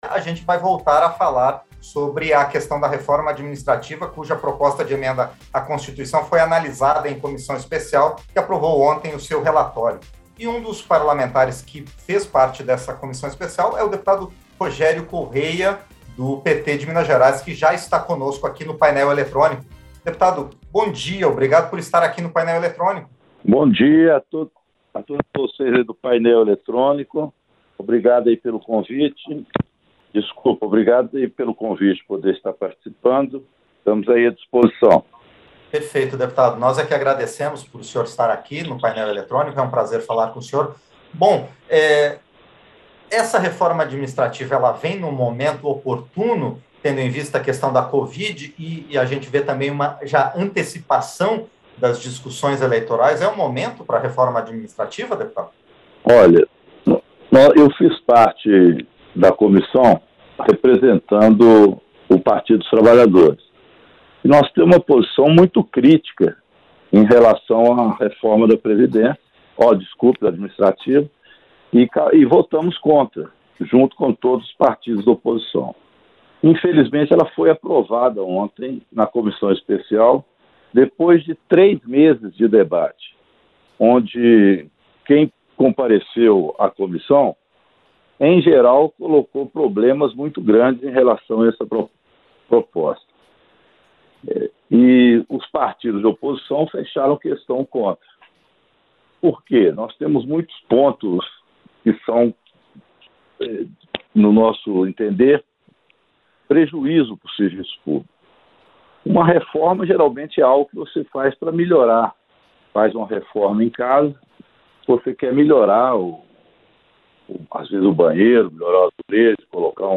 Entrevista - Dep. Rogério Correia (PT-MG)